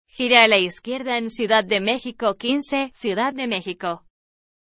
Entidad Federativa Prefijo Ejemplo TTS (Ariane)